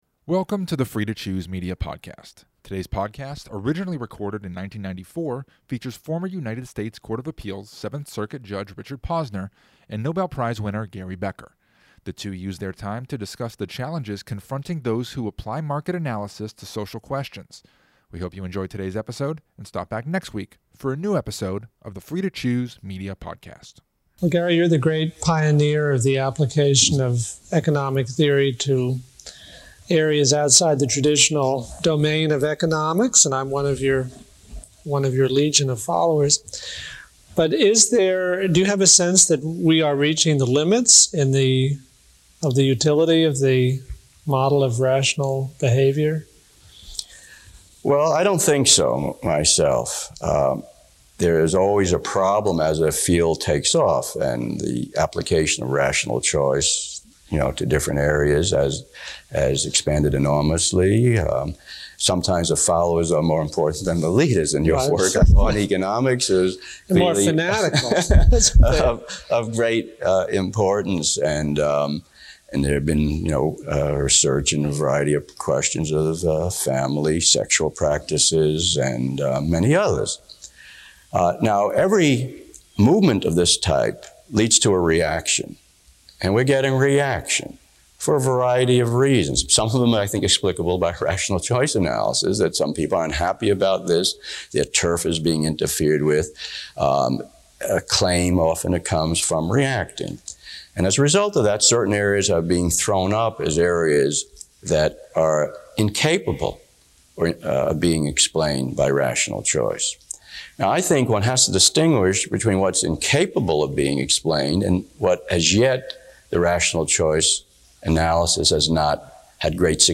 Today's podcast features former United States Court of Appeals, Seventh Circuit, Judge Richard Posner, and former Nobel Prize winner Gary Becker. The two use their time to discuss the challenges confronting those who apply market analysis to social questions.